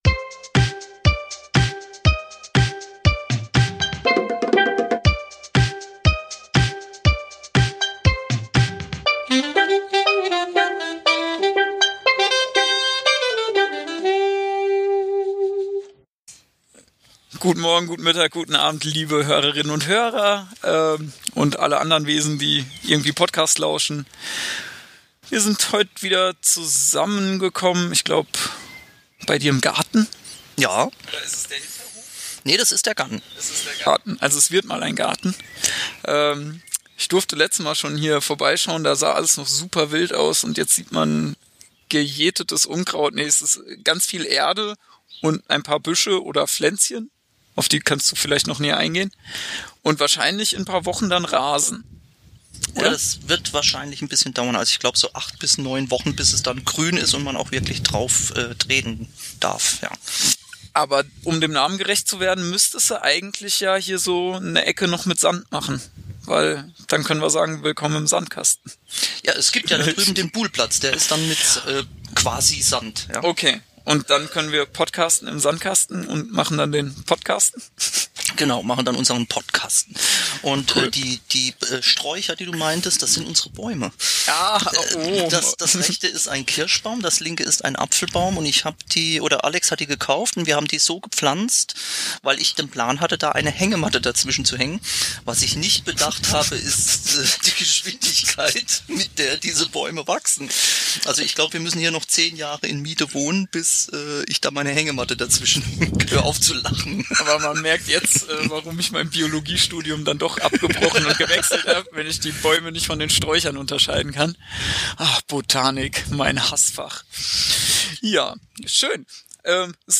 Wir reden über alles mögliche. Themen haben wir nicht vorbereitet, uns spontan getroffen (bei mir im Garten) und losgelegt.